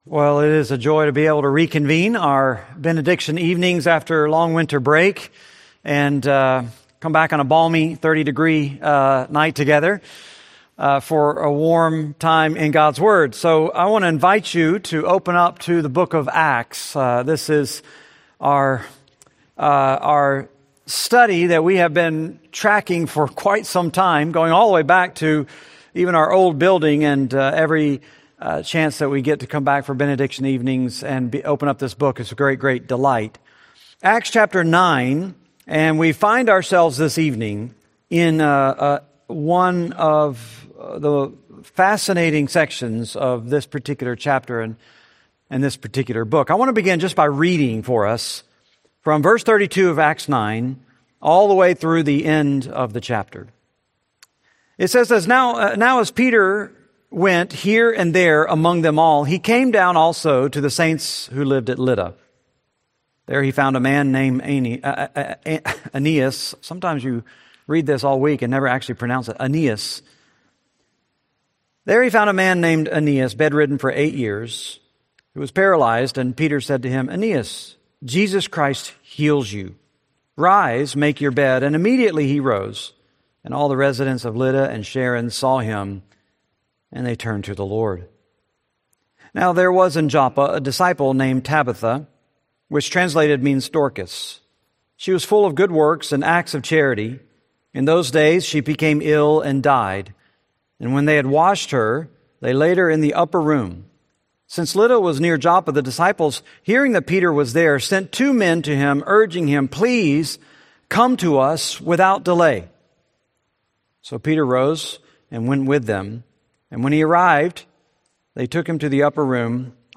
Series: Benediction Evening Service, Bible Studies